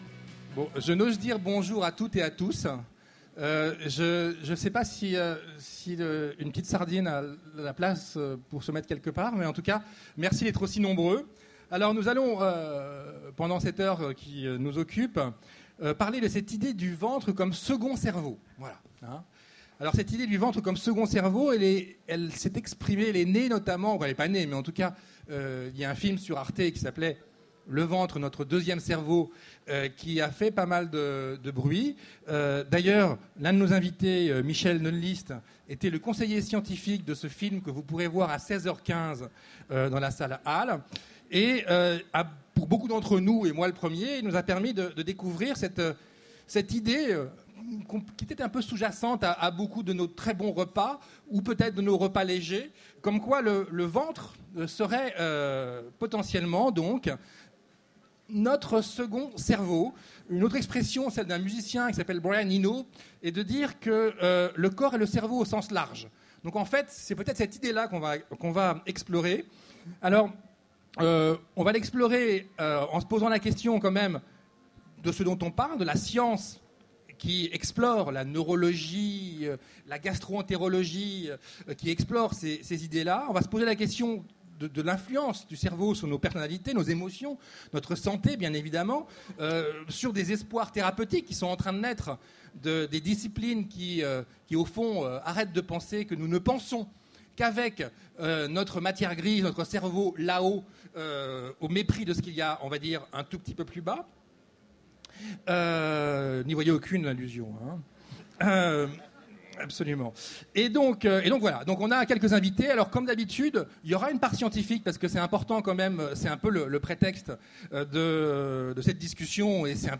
Mots-clés sciences Conférence Partager cet article